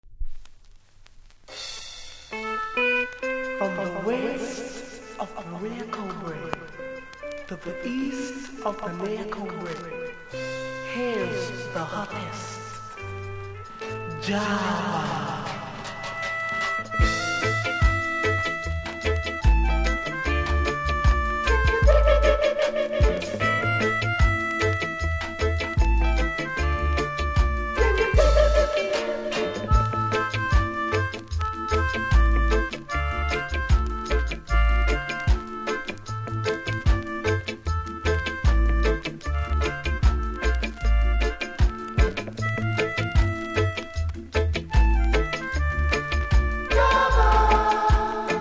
プレス起因だと思いますが序盤1発だけノイズあります。